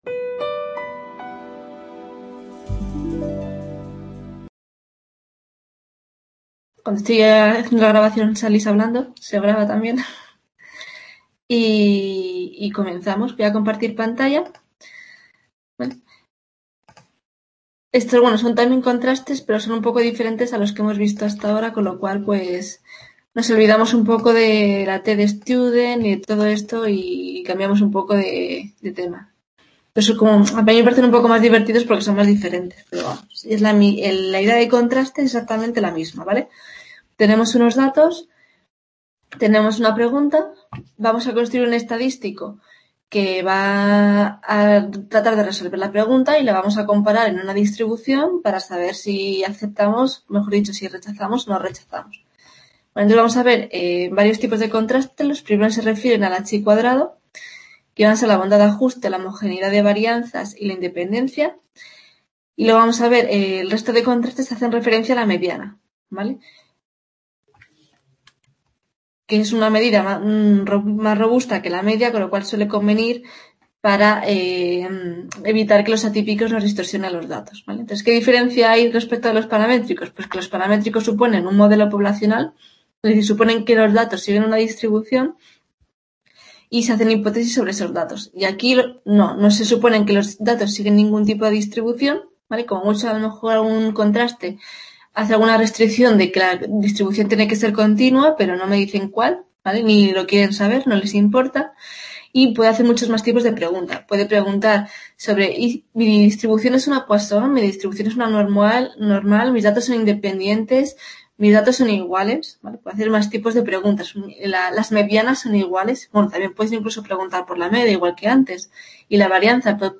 Tutoria Estadística Básica. Grado Matemáticas.